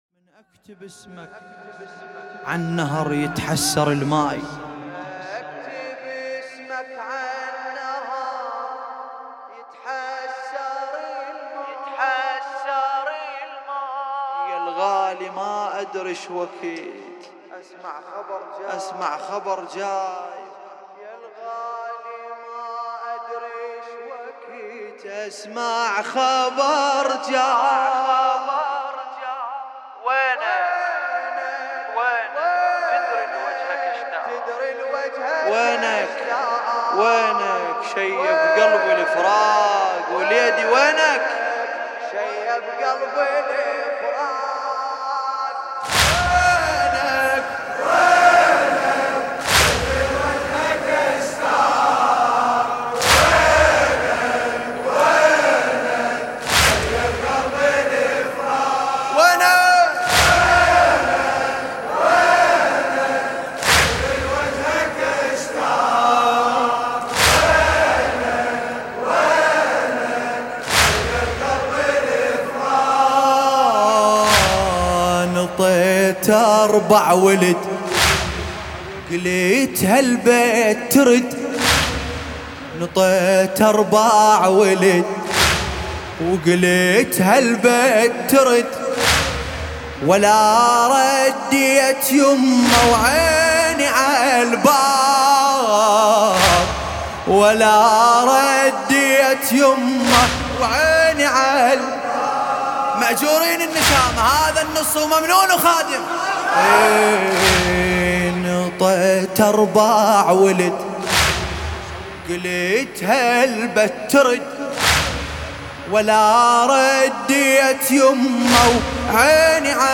حسینیه انصارالحسین علیه السلام